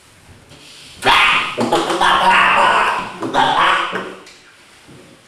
Audio files for the following article: Aerial Vocalizations by Wild and Rehabilitating Mediterranean Monk Seals (Monachus monachus) in Greece
Pup Gaggle
pupgaggle.wav